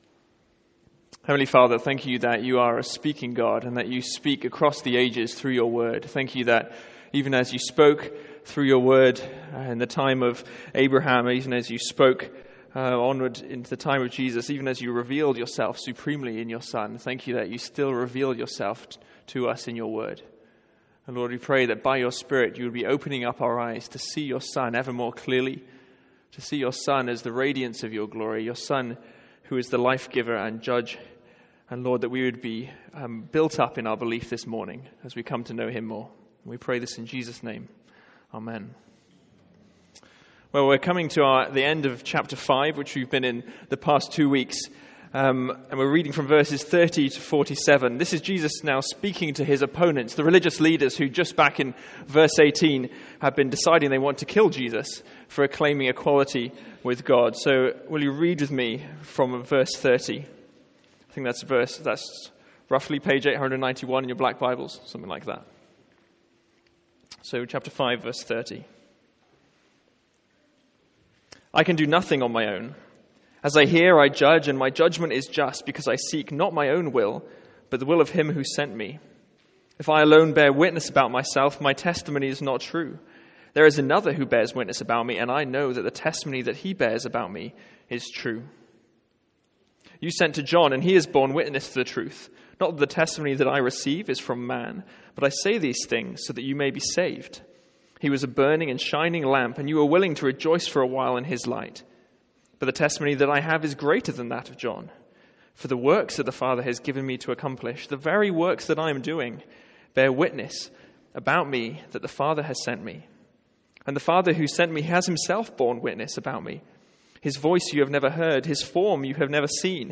Sermons | St Andrews Free Church
From our morning series in John's Gospel.